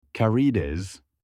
shrimp-in-turkish.mp3